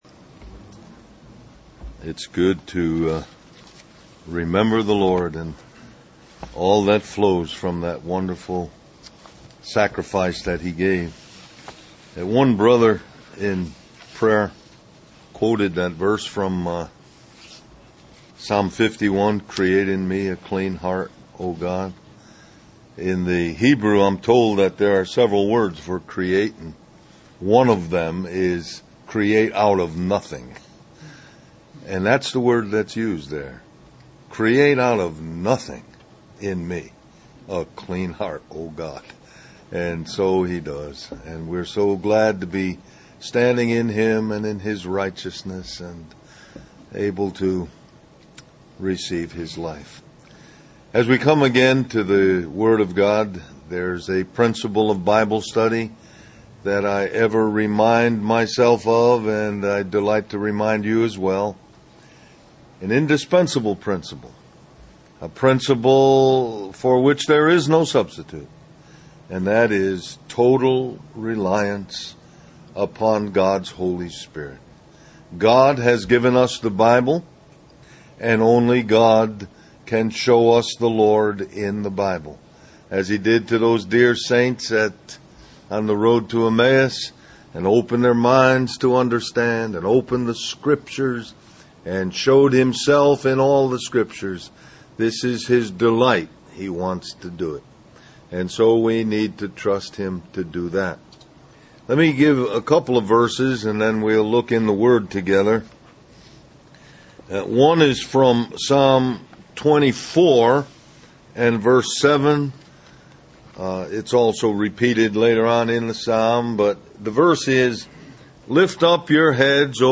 Del-Mar-Va Labor Day Retreat